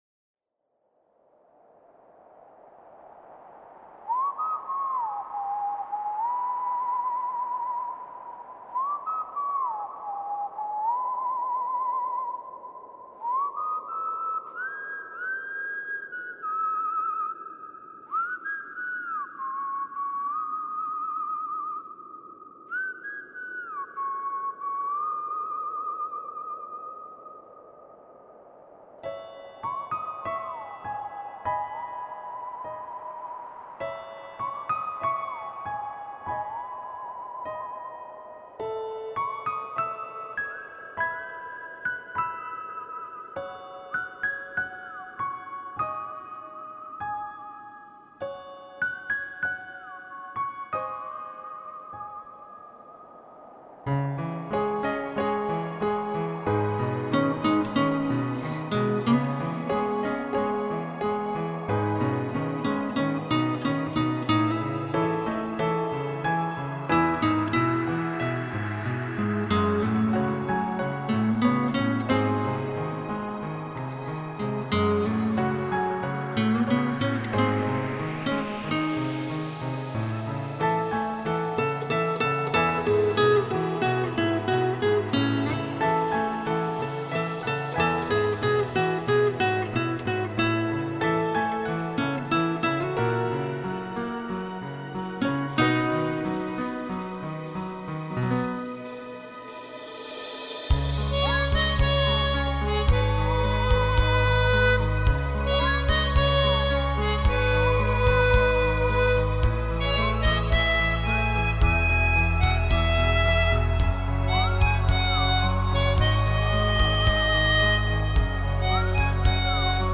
Progressive
A crescendo piece.
The following instruments were used on this piece: Roland HS 60, Roland SH 1, Roland XP 10, Roland Juno 2, Elka Solist 505, Roland JX 10, Korg 01/W, Formanta Polivoks, Roland D 50, ARP 2600, Roland S 550, Roland S 760, Roland SPD 20, Reason, Nostalgia, B4, Fender Stratocaster, Acoustic guitar, Harmonica, Triangle, Chimes, Cymbal, Whistle, Finger snap.